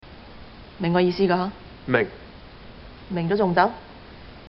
The movie is Triple Tap . Situation: At the investment bank where Gu Tin Lok ‘s character works, Boss Lady (who also happens to be Gu’s girlfriend…in the movie, that is) — played by Lei Bing Bing — chews out an underling.